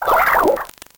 Cri de Nucléos dans Pokémon Noir et Blanc.